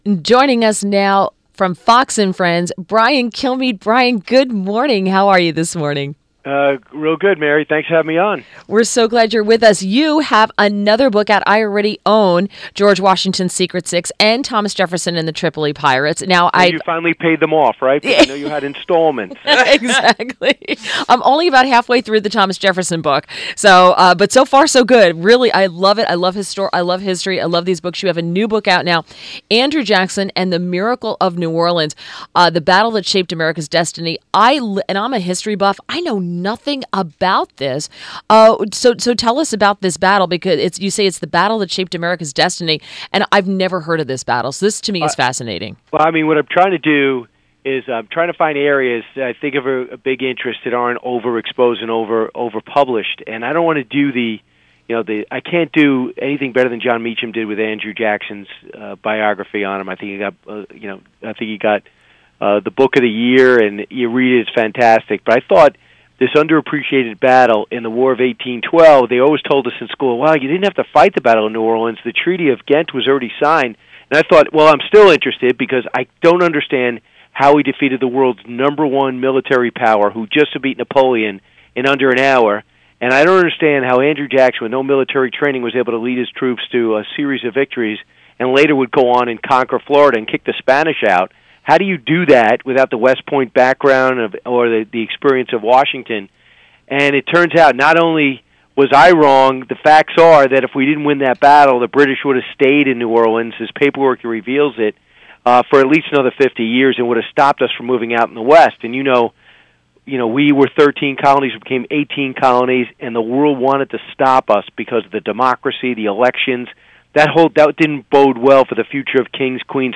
WMAL Interview - BRIAN KILMEADE - 10.26.17